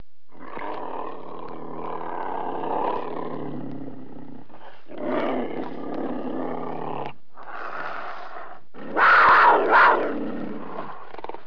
دانلود صدای حیوانات جنگلی 41 از ساعد نیوز با لینک مستقیم و کیفیت بالا
جلوه های صوتی